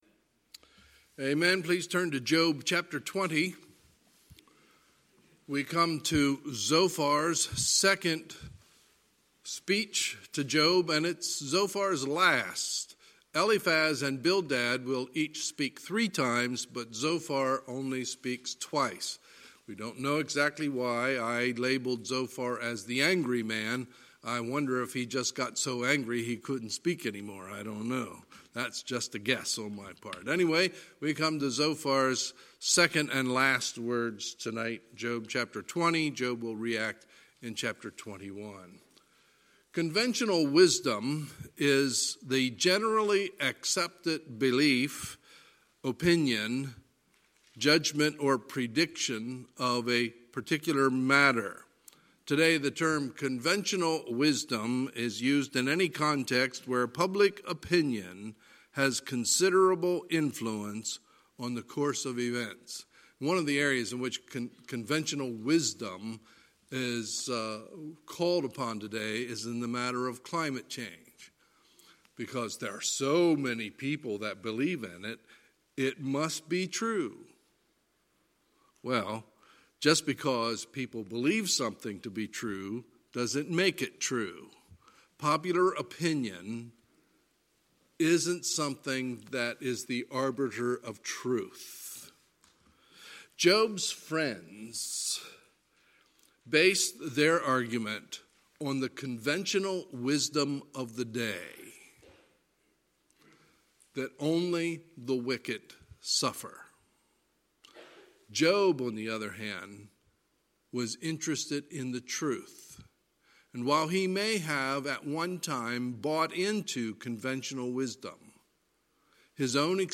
Sunday, March 1, 2020 – Sunday Evening Service